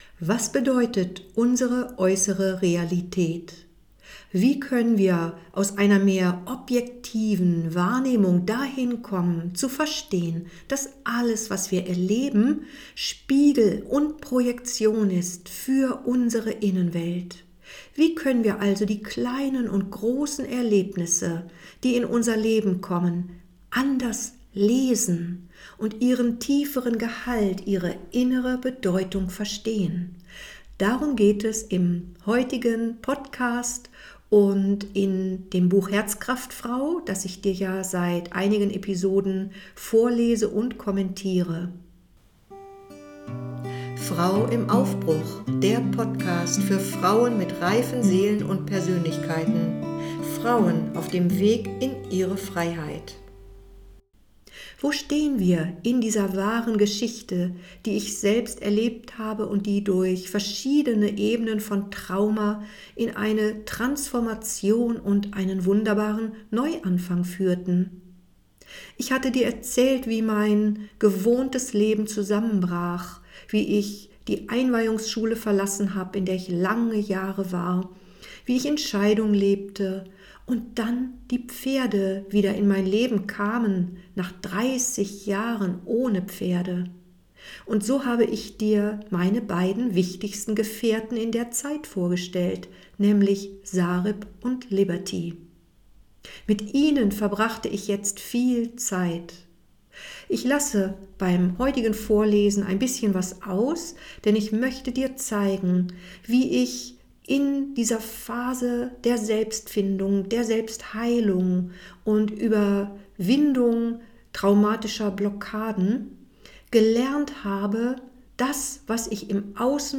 #009 Herzkraftfrau 5 - Lesung und Kommentar Wenn unser Leben sich verändert und wir viele Fragezeichen in unserem Innern mit uns herumtragen, können wir die uralte Sprache der Seele aktivieren, um Antworten zu finden.